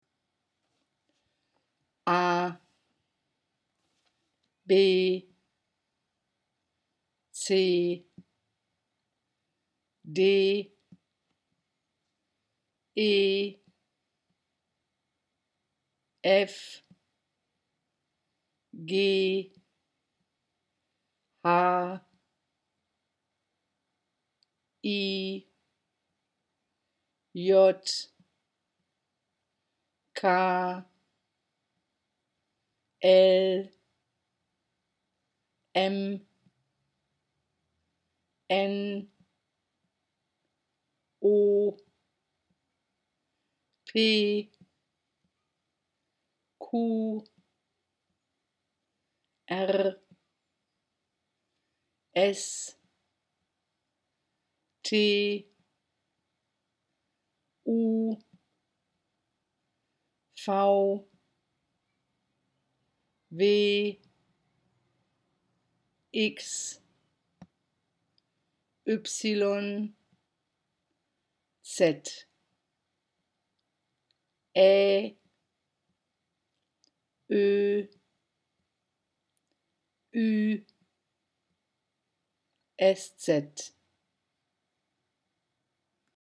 15: The pronunciation of the German Alphabet
ABCUmlaut-1.mp3